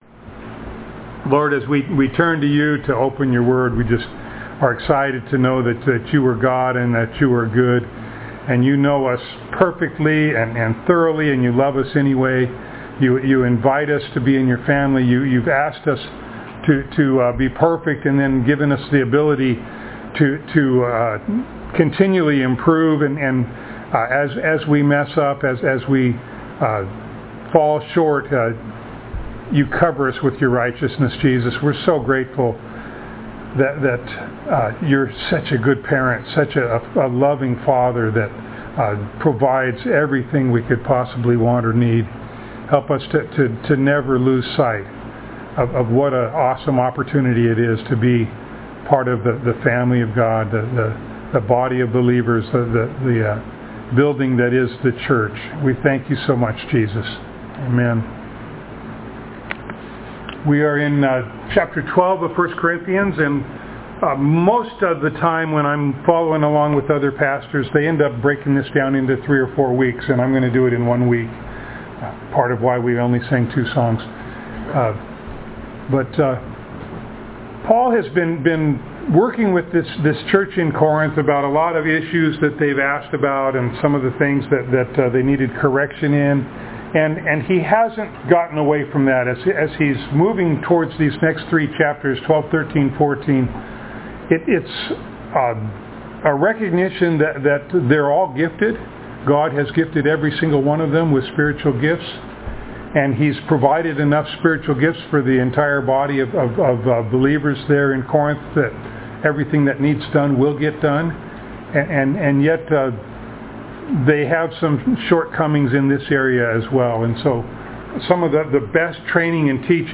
1 Corinthians Passage: 1 Corinthians 12:1-31 Service Type: Sunday Morning Download Files Notes « In Remembrance!